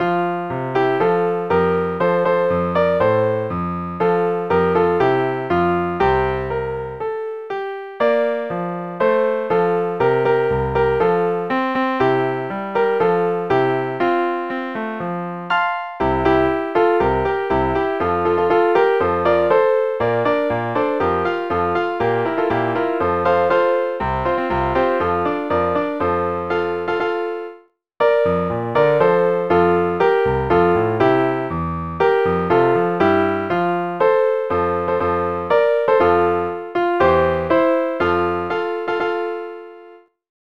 学校沿革史 校章 校歌 校歌音楽